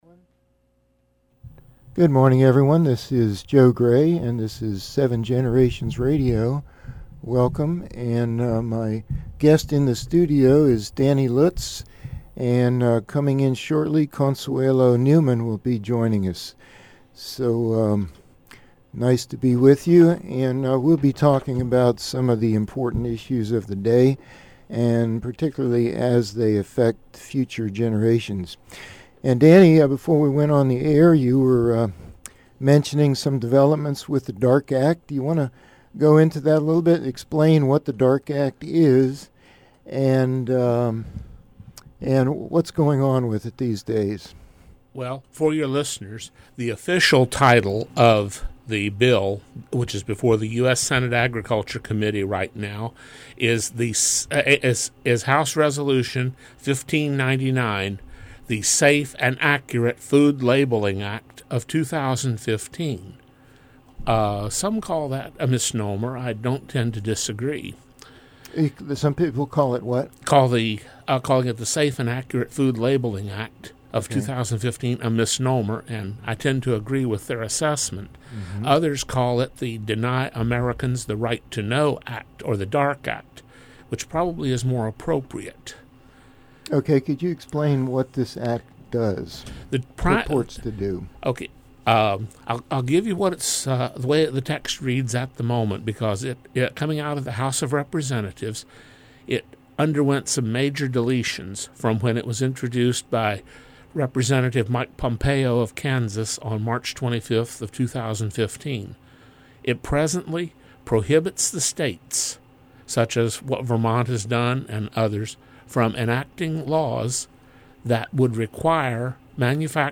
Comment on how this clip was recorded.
Audio recording of radio broadcast at WSHC Shepherdstown from the campus of Shepherd University, 8/29/15